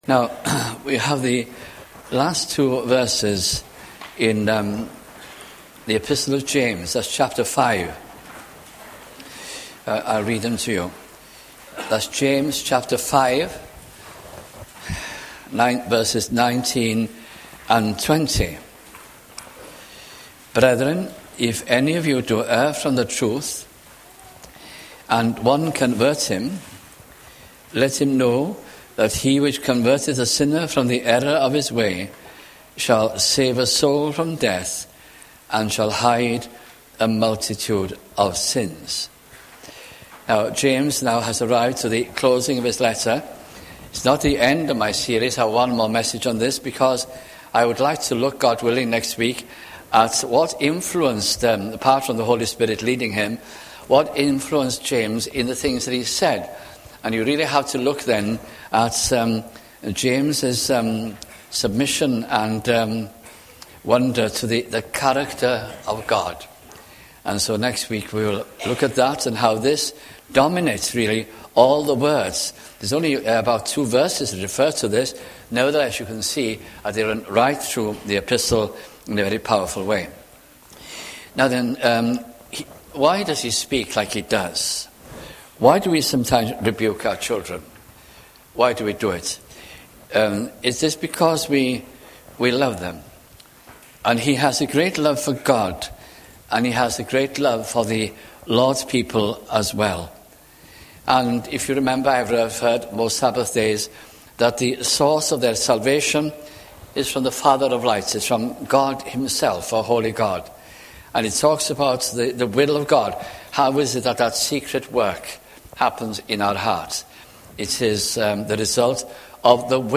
» James 2002 » sunday morning messages